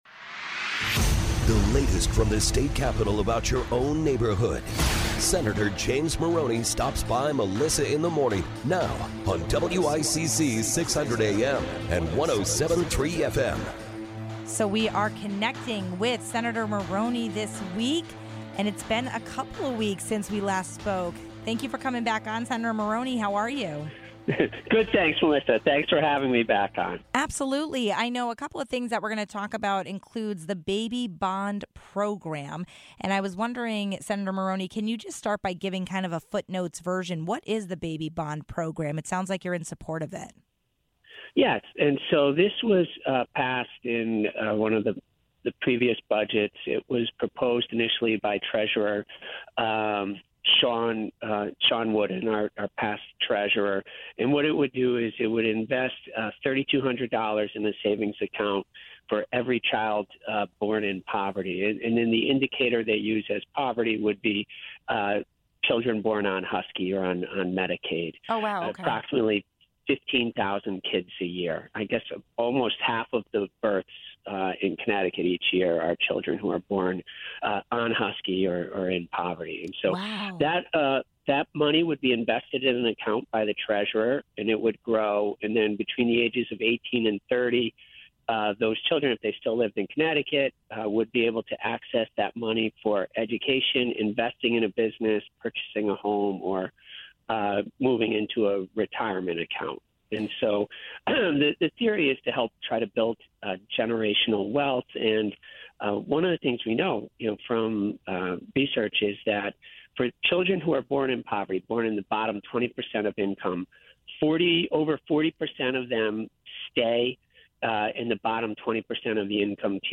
We are down to about one month left before the 2023 legislative session wraps up. Senators James Maroney and Tony Hwang spoke about their biggest items they are working on right now: